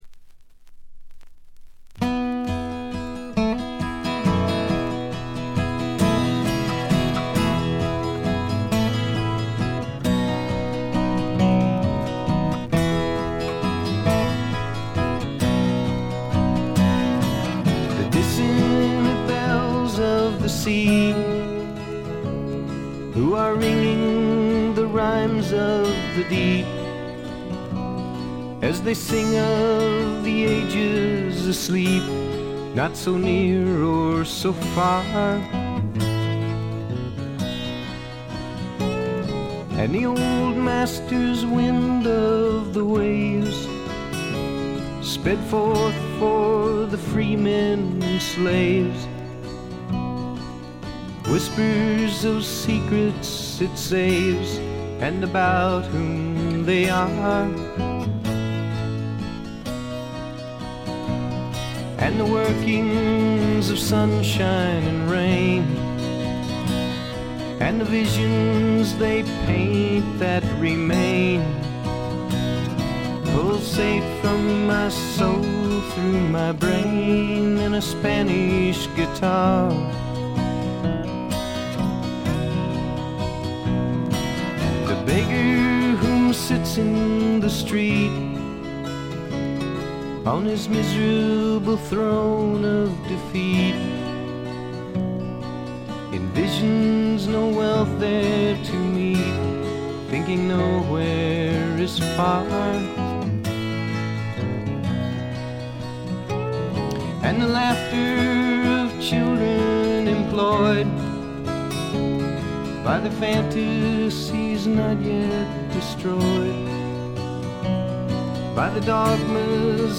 ほとんどノイズ感無し。
試聴曲は現品からの取り込み音源です。
Recorded at The Village Recorder